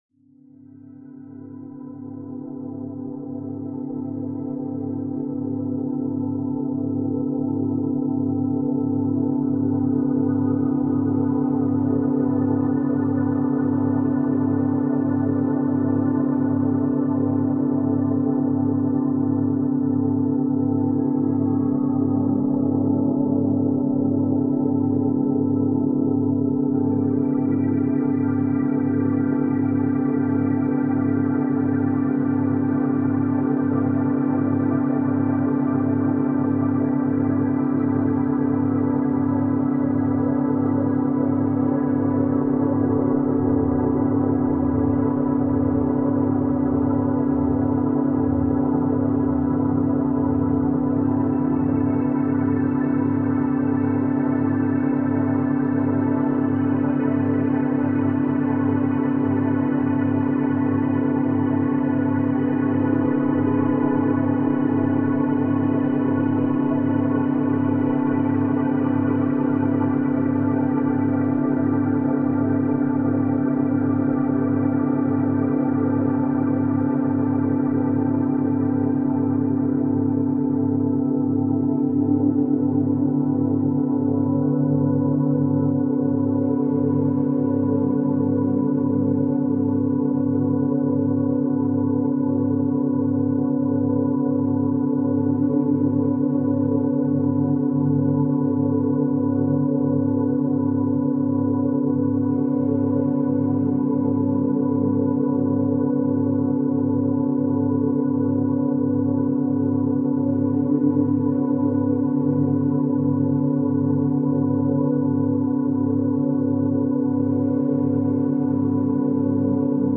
Pomodoro 2h : Pluie et Focus